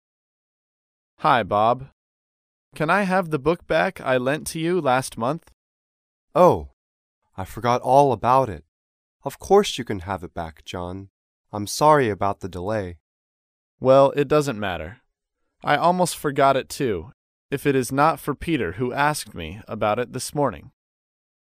高频英语口语对话 第30期:要还物品 听力文件下载—在线英语听力室
在线英语听力室高频英语口语对话 第30期:要还物品的听力文件下载,《高频英语口语对话》栏目包含了日常生活中经常使用的英语情景对话，是学习英语口语，能够帮助英语爱好者在听英语对话的过程中，积累英语口语习语知识，提高英语听说水平，并通过栏目中的中英文字幕和音频MP3文件，提高英语语感。